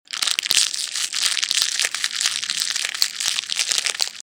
ENVOLTURA PAPEL
Tonos gratis para tu telefono – NUEVOS EFECTOS DE SONIDO DE AMBIENTE de ENVOLTURA PAPEL
envoltura_papel.mp3